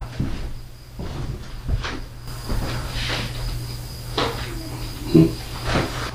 Iowa Paranormal Investigations
Before the sentence I also hear a one word child voice, and a harsh whisper voice saying maybe, "Hide." show/hide spoiler Back to Villisca Axe Murder House Investigation Evidence Page